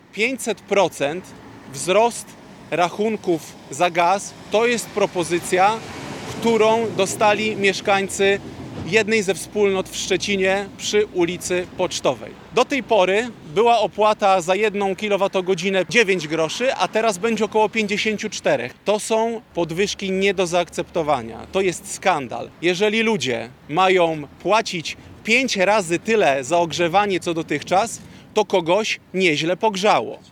Zachodniopomorscy politycy Platformy Obywatelskiej: Magdalena Filiks, Arkadiusz Marchewka, Sławomir Nitras i Grzegorz Napieralski na dzisiejszej konferencji prasowej przedstawili projekt ustawy, która ma uchronić Polaków przed nadmiernym wzrostem cen gazu.
Konferencja odbyła się w pobliżu szczecińskiego Biura Obsługi Klienta PGNiG, gdzie przed jego wejściem, interesanci czekali w długiej kolejce na załatwienie swoich spraw.